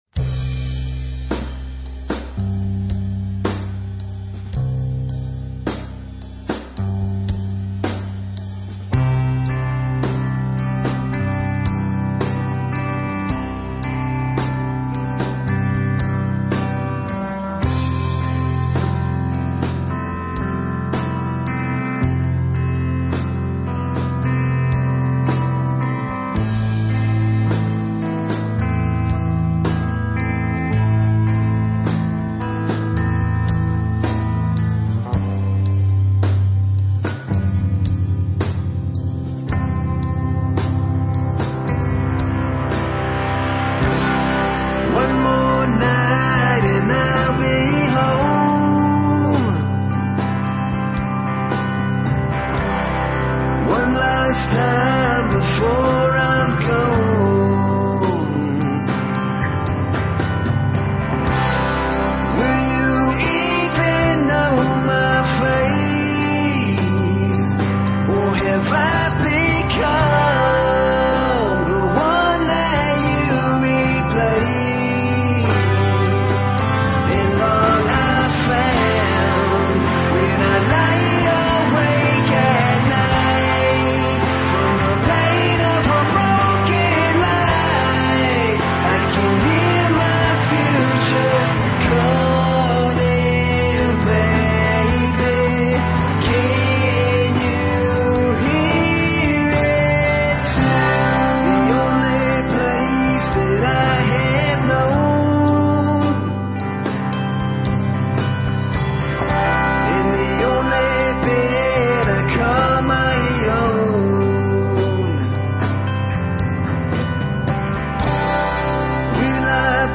rock
punk
metal
high energy rock and roll